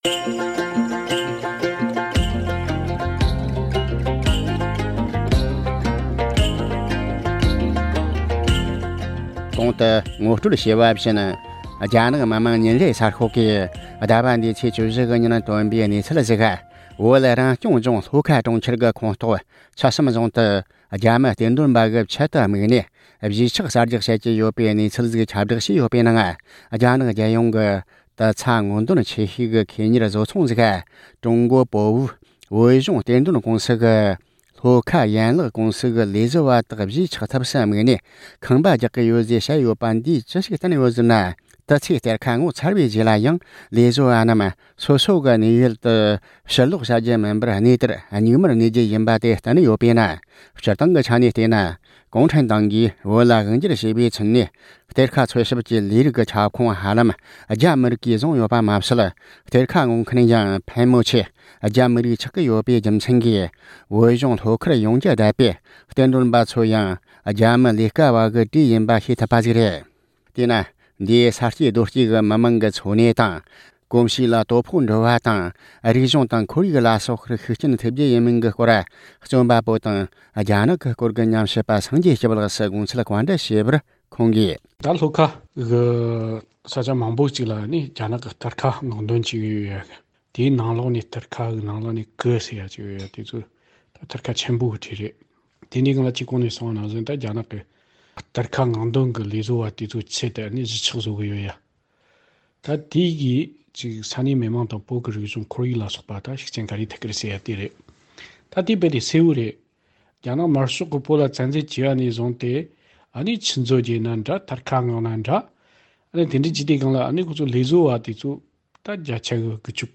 བོད་ལྗོངས་ལྷོ་ཁ་ཕྱོགས་སུ་རྒྱ་མི་གཏེར་འདོན་པའི་ཆེད་དུ་གཞིས་ཆགས་གསར་རྒྱག་བྱེད་ཀྱི་ཡོད་པའི་སྐོར་ཉམས་ཞིབ་པར་བཀའ་འདྲི་ཞུས་པ།